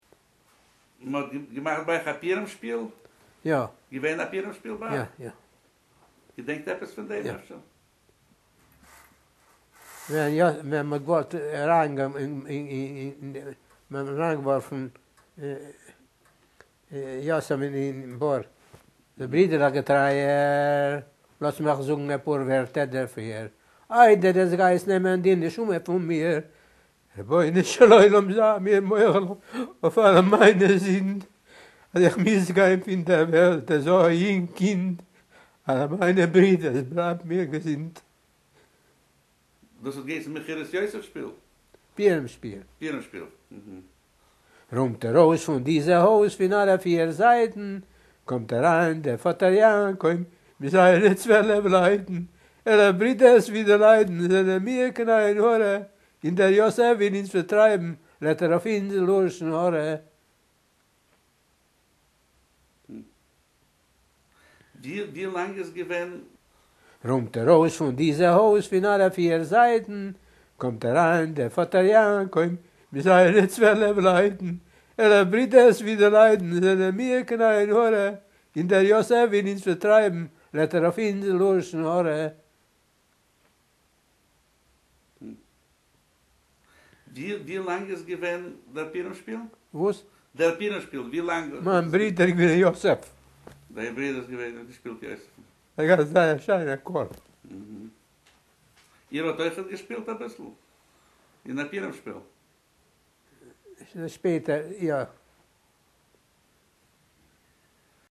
Recorded in Debrecen, December 2006
Purimshpil-Yoysef.mp3